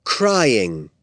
CRYING.mp3